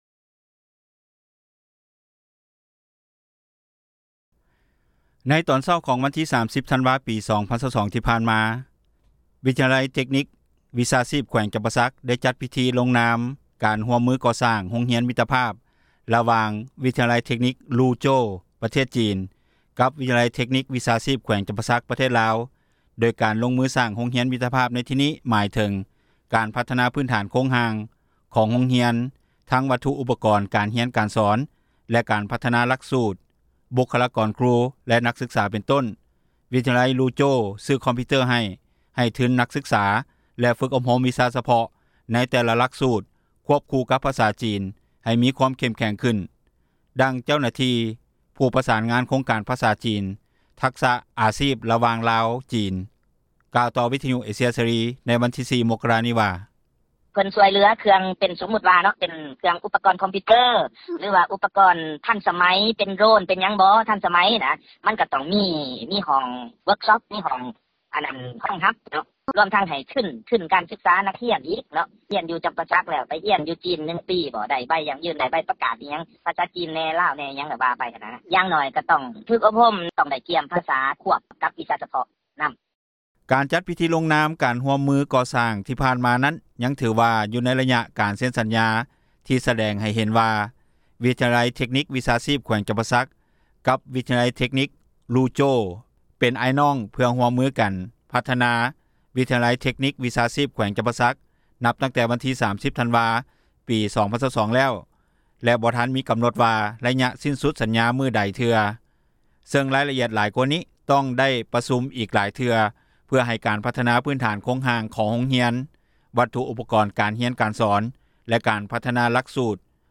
ດັ່ງອາຈານ ວິທຍາໄລ ເທັກນິກ-ວິຊາຊີພ ແຂວງ ຈຳປາສັກ ອີກຜູ້ນຶ່ງກ່າວ ໃນມື້ດຽວກັນນີ້ວ່າ:
ດັ່ງນັກສຶກສາ ວິທຍາໄລ ເທັກນິກ-ວິຊາຊີພ ແຂວງຈຳປາສັກ ກ່າວໃນມື້ດຽວກັນນີ້ວ່າ: